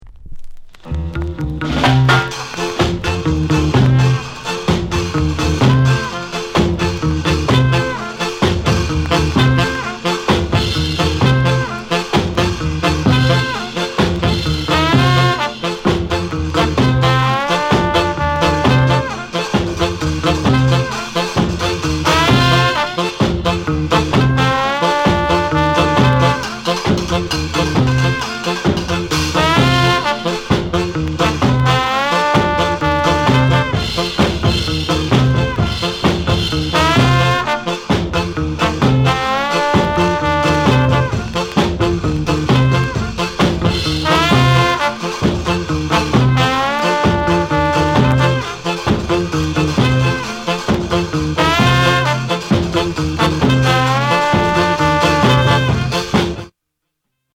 RARE SKA INST